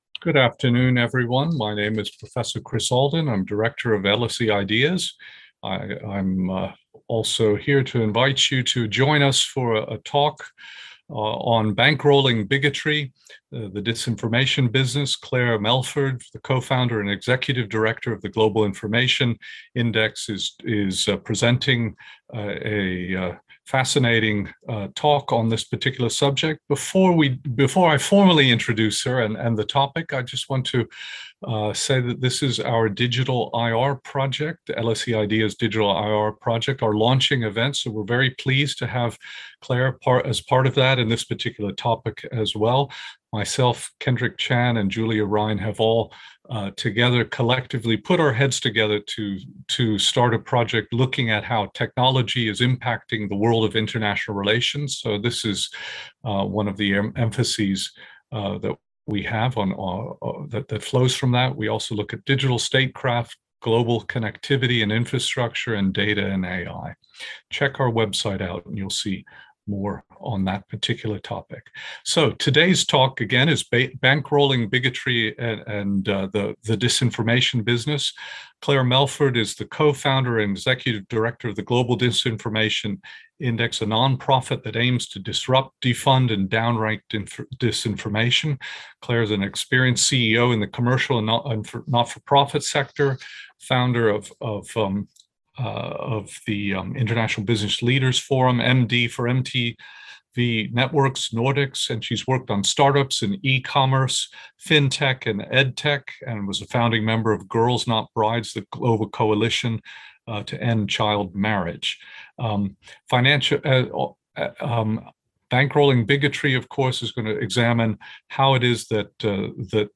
Watch Bankrolling Bigotry Listen Bankrolling Bigotry This webinar was held on Tuesday 9 November 2021.